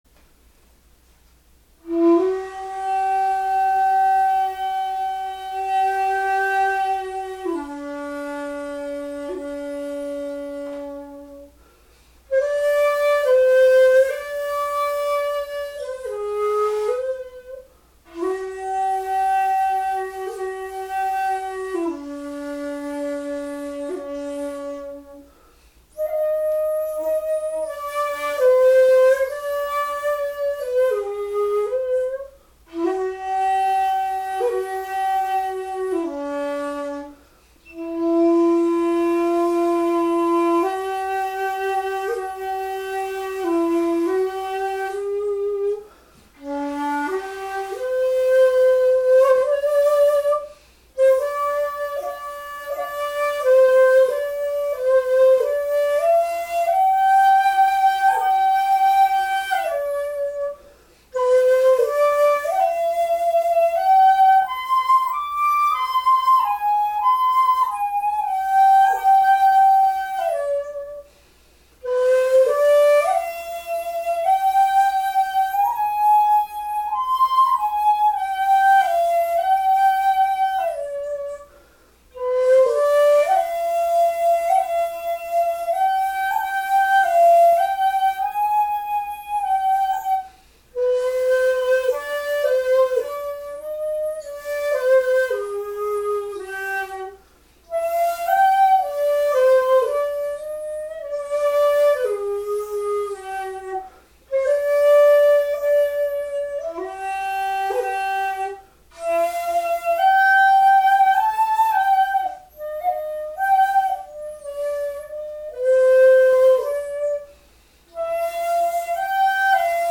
華麗な良く響く音色で、しかも地歌を吹くためにッのメリが出やすいように製作した竹があります。
地塗りなのにッのメリ音の音色が潰れてしまわない、そして舞台でも映える響く上品な音色です。
まだまだ修行途上の私が吹いても、その地塗りの華やかな響きがわかるかと存じます。
但しッメリの音を潰さないように「ツ」の全音は「かなり低い」。